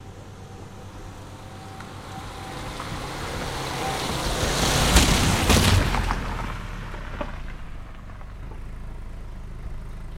Car On Wood Bridge, Splashes Exterior